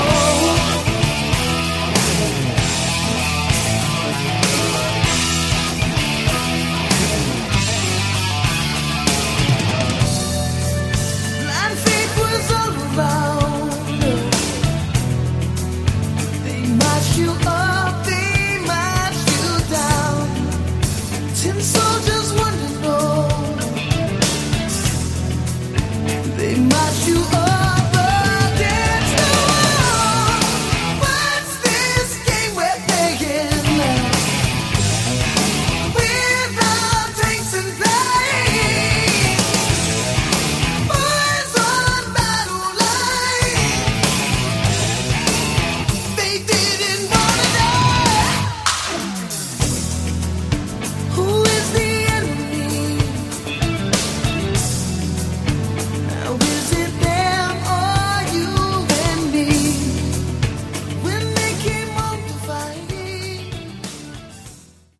Category: Hard Rock
guitars, backing vocals
bass, keyboards, backing vocals
lead vocals
drums, percussion